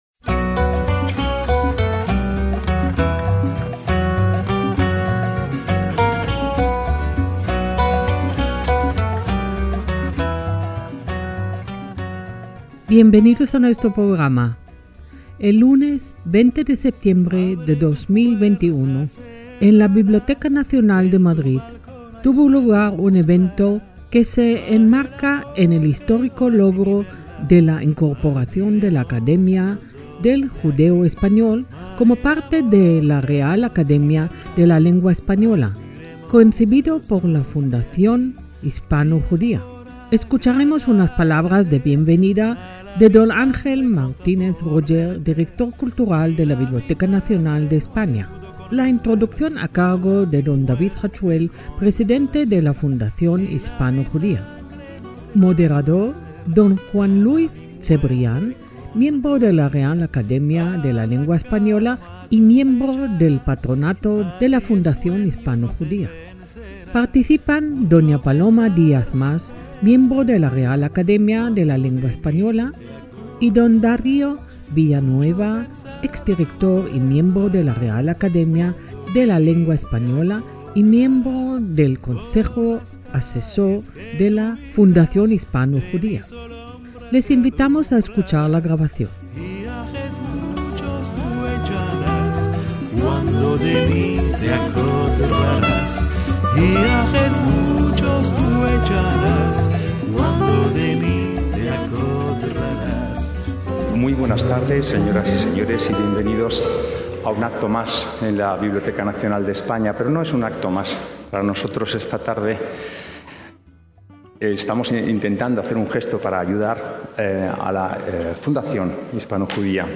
ACTOS EN DIRECTO - En el mes de la cultura judía en España, enmarcado por la majestuosa Biblioteca Nacional, la Fundación HispanoJudía celebró el 20 de septiembre de 2021 un acto histórico para la puesta en valor del judeoespañol.